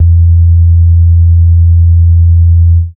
Bass (5).wav